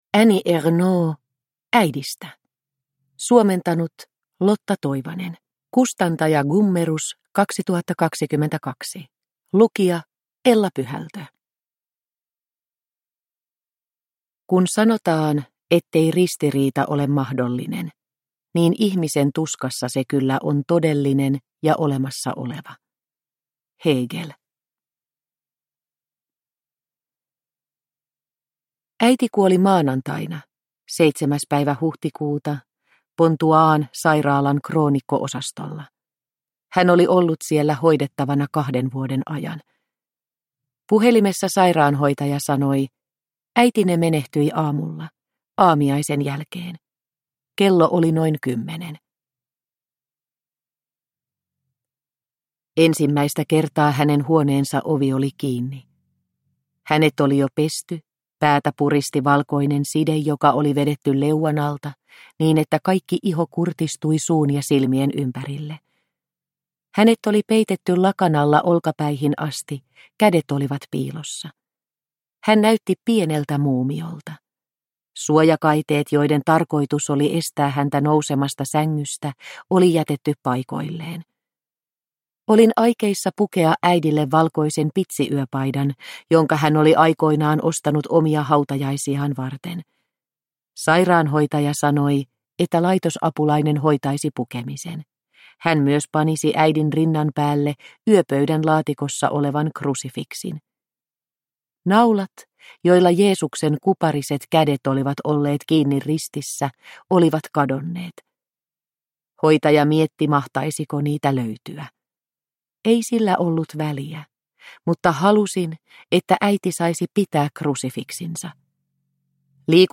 Äidistä – Ljudbok – Laddas ner